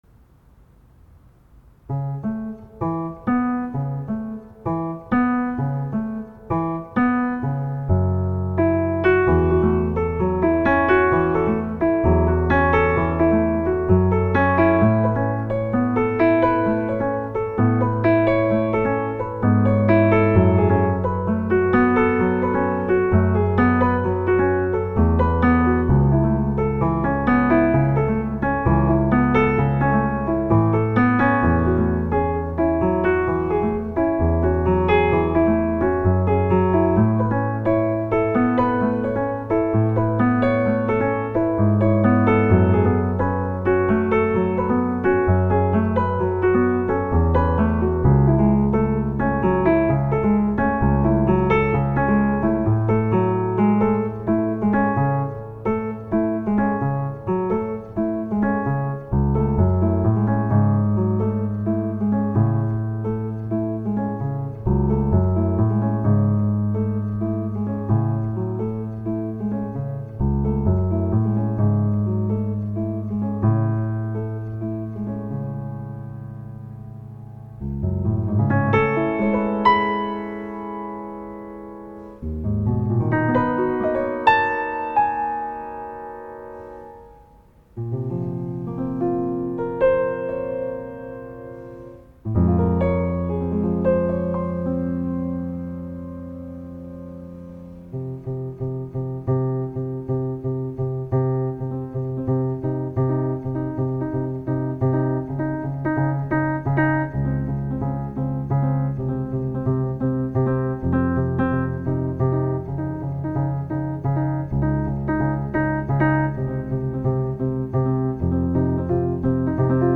Une petit vibe Strobe/Deadmau5 matinée d'Aphex Twin période Druqs.
Une petite compo pour pianoteq : piano_poly.mp3